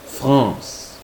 法蘭西共和國（法語：République française發音：[ʁepyblik fʁɑ̃sɛːz] ），通稱法國France [fʁɑ̃s]